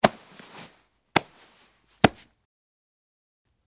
Cloth, Grab
Body Pats And Grabs With Cloth Movement, X2